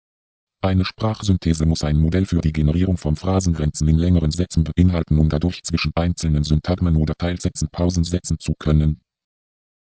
Ein Text ohne solche Grenzen, atemlos zu Ende gehetzt, ist schwer verstehbar.